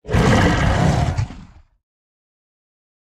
beast_roar_normal.ogg